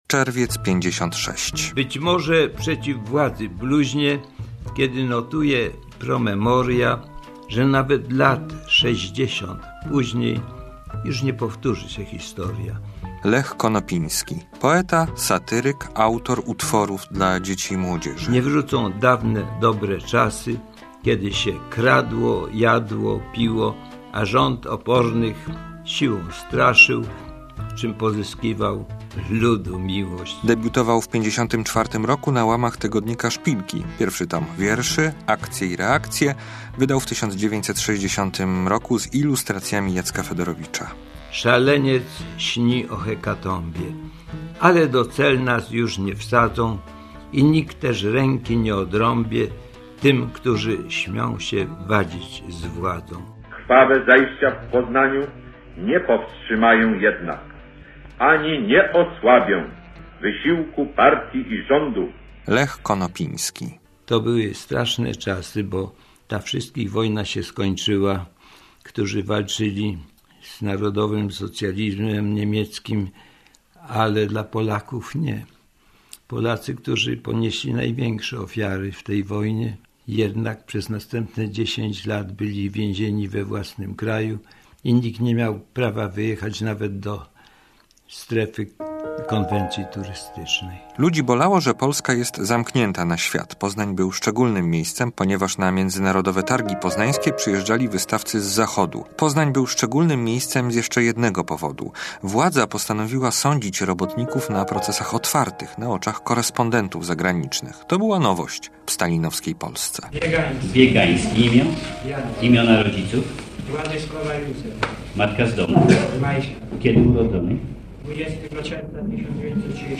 hnbz48mo545ptpy_reportaz_glosy_poznanskiego_czerwca.mp3